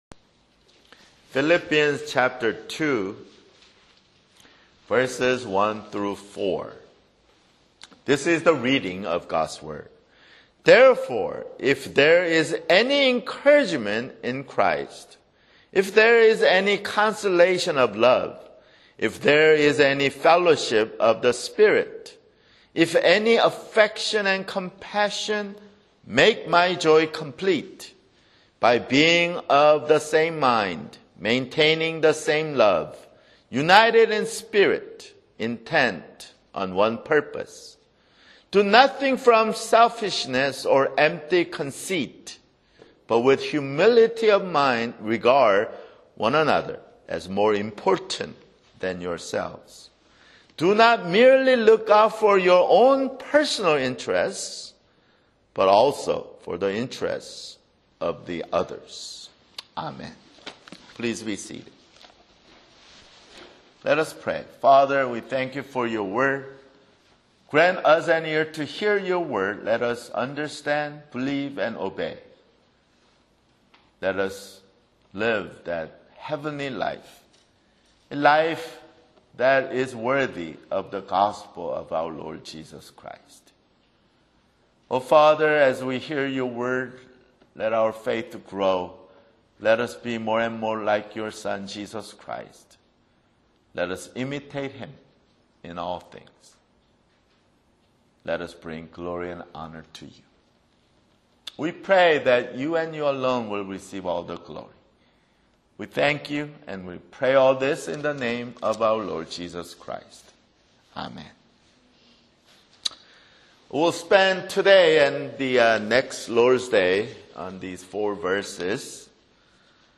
[Sermon] Philippians (21)